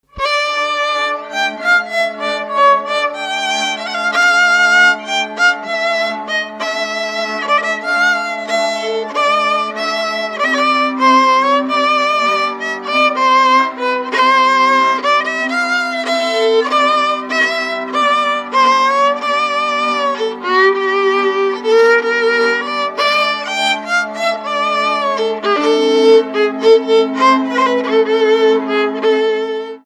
Dallampélda: Hangszeres felvétel
Erdély - Szolnok-Doboka vm. - Magyardécse
hegedű
kontra (háromhúros)
Műfaj: Lassú csárdás
Stílus: 4. Sirató stílusú dallamok
Kadencia: 5 (b3) 4 1